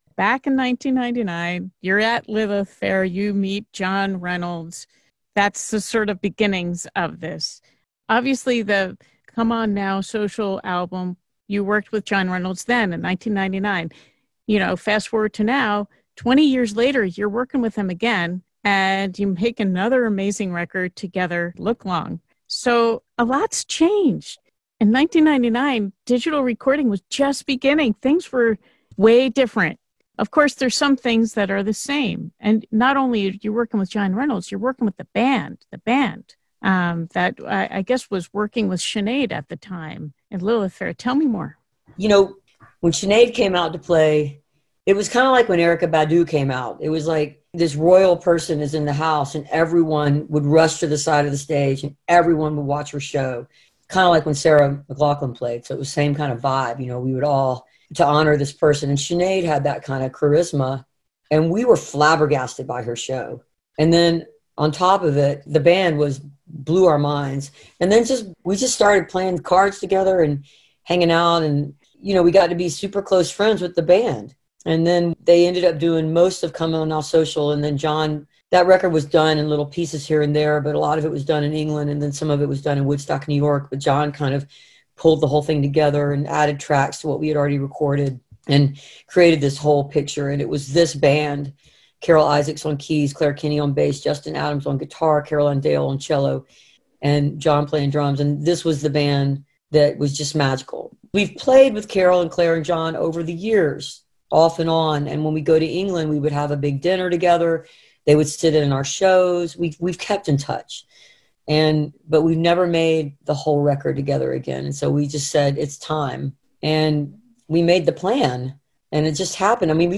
(captured from the web broadcast)
06. interview (2:41)